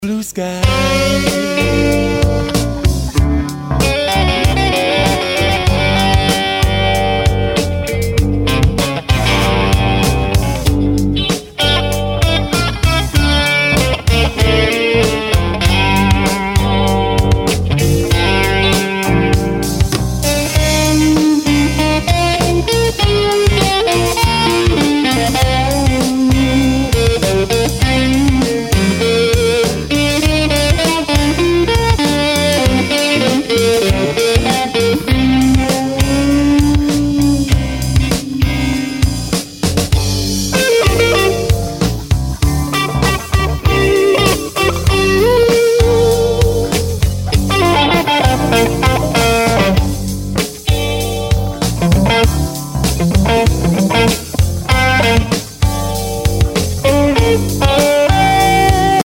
Auf der Aufnahme hört ihr einen direkten Vergleich zwischen (m)einem alten Kitty Hawk Junior Pro 12" über eine Marshall Silver Jubilee 2x12" gegen einen Boogie Mark 3 über seinen Speaker. aufgenommen in eienem Tonstudio in Troisdorf bei Bonn ca. 1991 . Der erste und dritte Part ist mein Solo Kitty (Crunch) aus einer Aria Pro 2 Stagecaster.